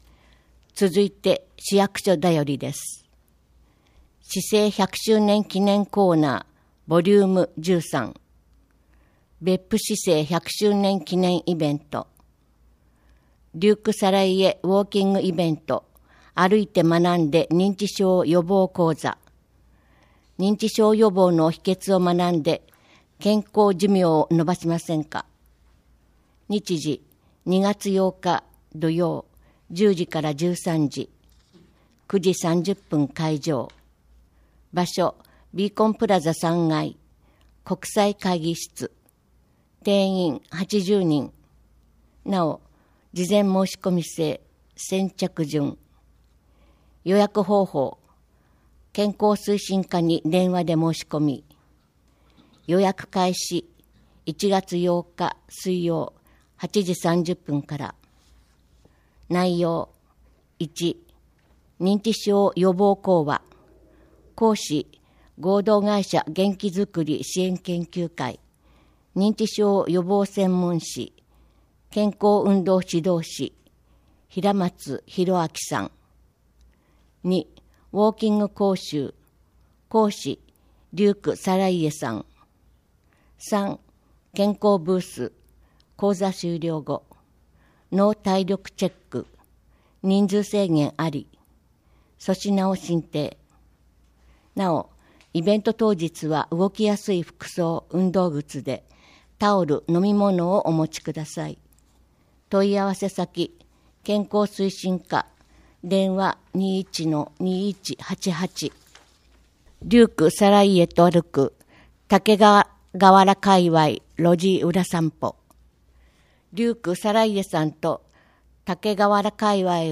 毎月市報べっぷの中から、視覚に障がいがある皆さんに特にお知らせしたい記事などを取り上げ、ボランティアグループ「わたげの会」の皆さんに朗読していただいて作られています。